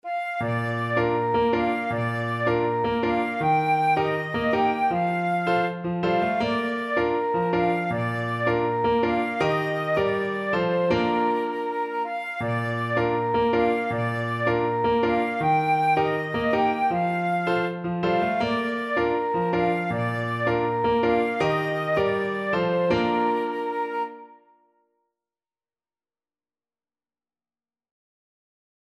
Flute
Traditional Music of unknown author.
2/4 (View more 2/4 Music)
Bb5-G6
Bb major (Sounding Pitch) (View more Bb major Music for Flute )
Steadily =80
Cameroonian